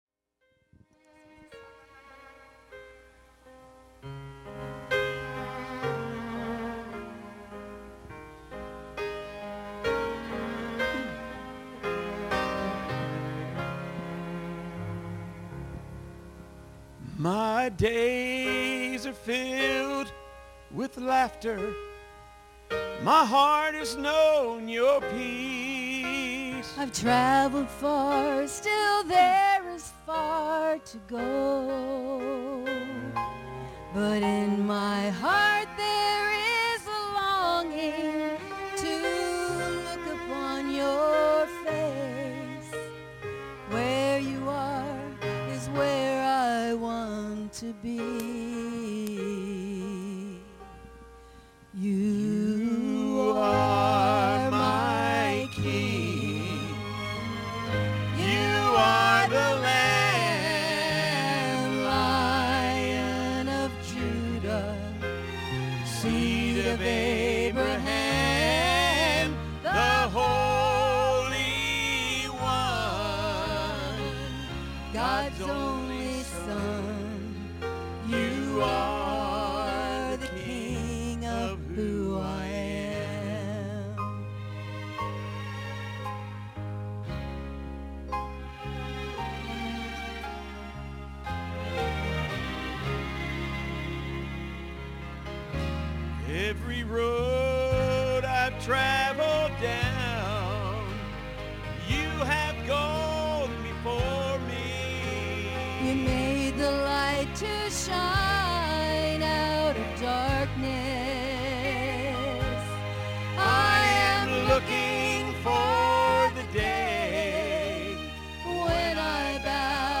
Music Archive